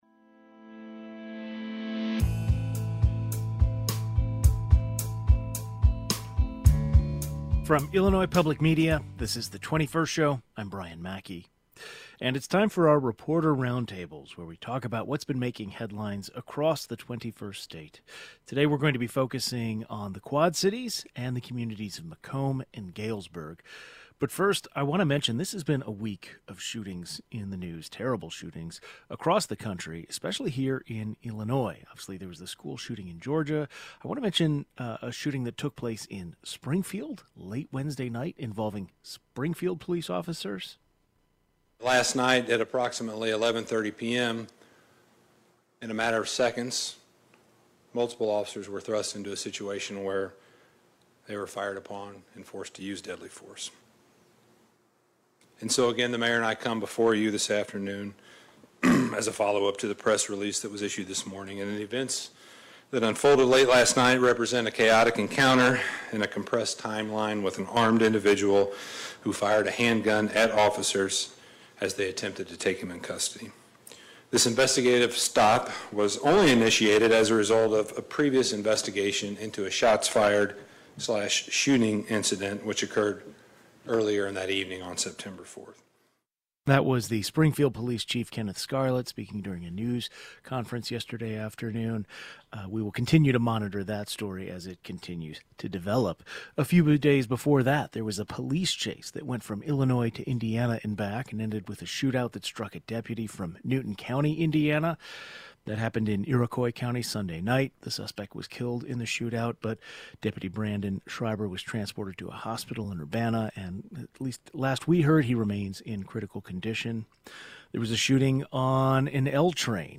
Two Illinois Public Radio news directors join the conversation.